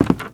High Quality Footsteps
STEPS Wood, Creaky, Walk 12.wav